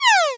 Laser_Shot.m4a